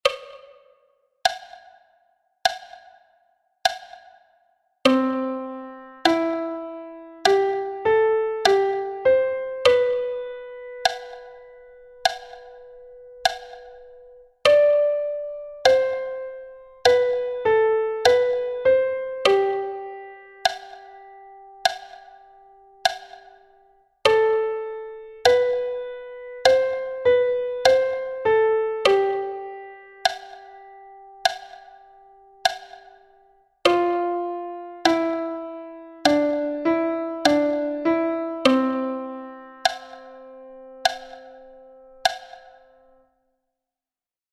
The two first exercises have got the metronome sound and an only metronome bar at the beginning.